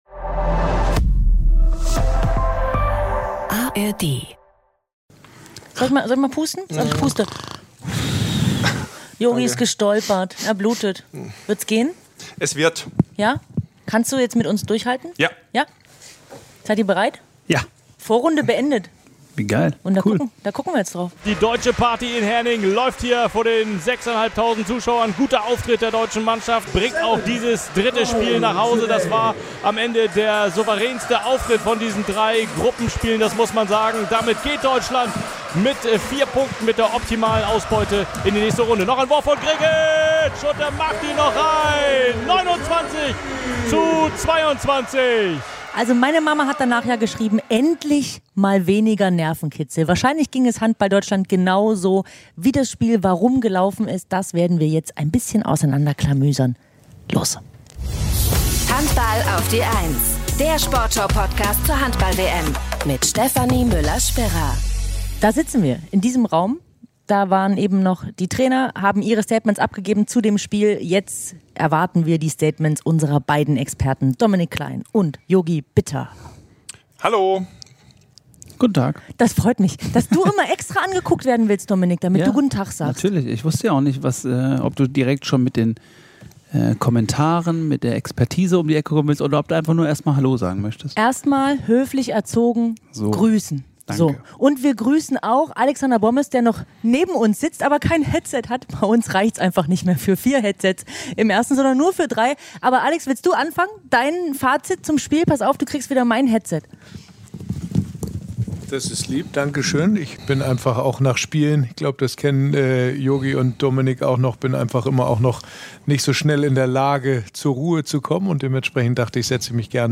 Die Erleichterung bei Deutschlands Handballern nach dem dritten Sieg im dritten Spiel ist groß. Matchwinner David Späth, die Rückraumspieler Renars Uscins und Juri Knorr – ihr hört sie alle bei uns im Podcast.
Besuch gibt es auch mal wieder: Alex Bommes kommt vorbei und unterstützt mit fernöstlichen Weisheiten.